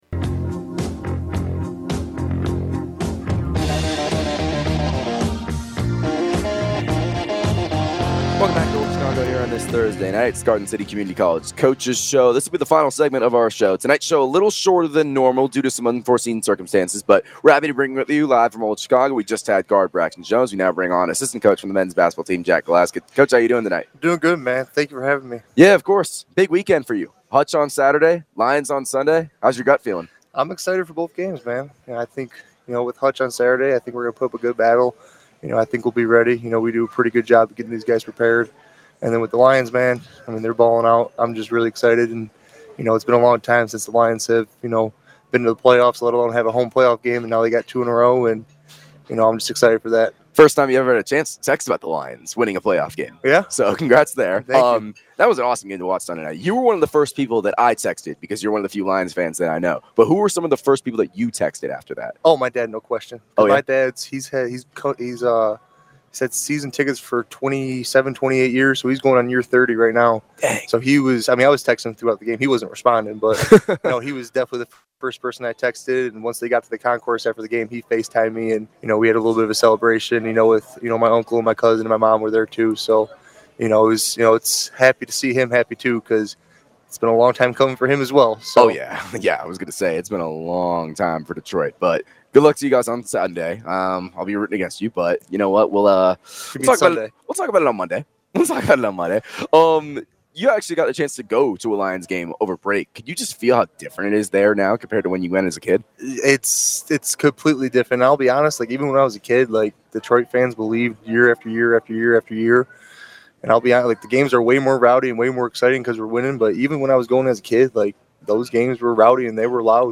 GARDEN CITY, KS. – The Garden City Community College Coaches Show returned Thursday night from Old Chicago Pizza & Taproom, with a special focus on one team’s major win.